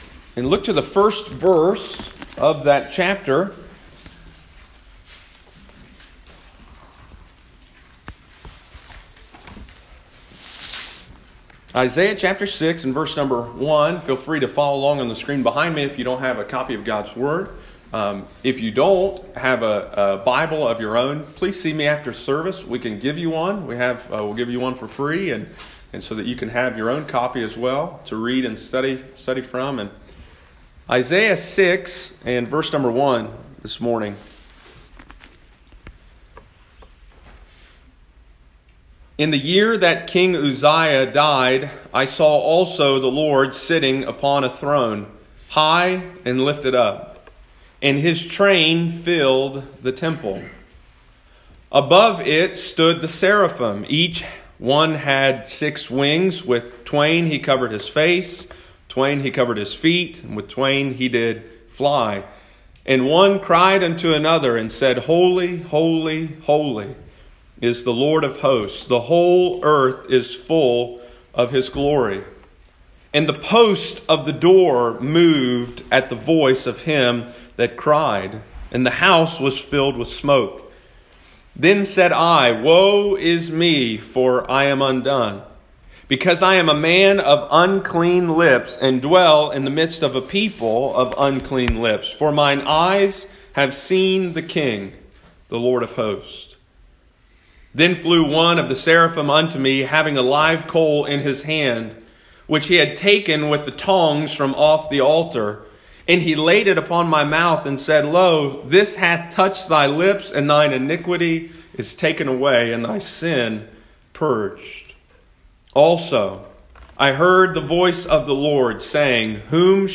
Sunday Morning, March 10, 2019.